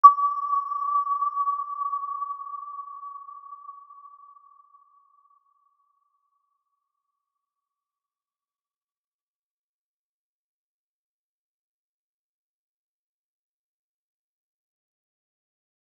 Aurora-E6-mf.wav